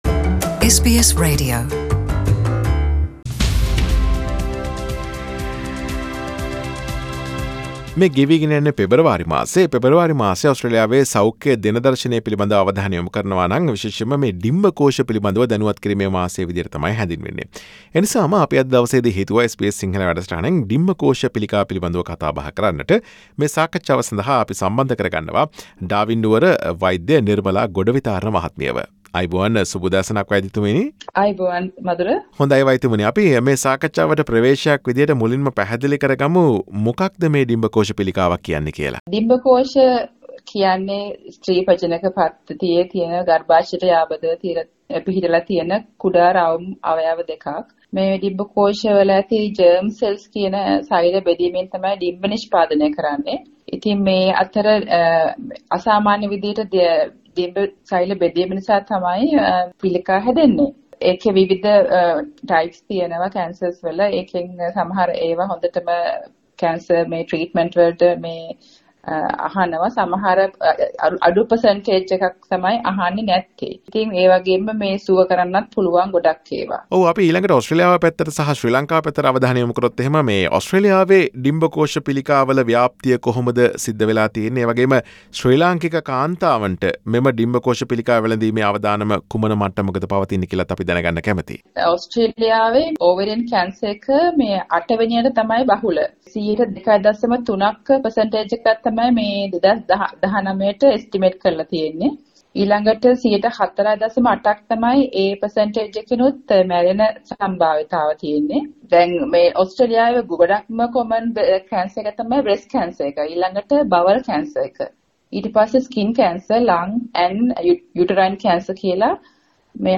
සිදු කළ සාකච්ඡාව.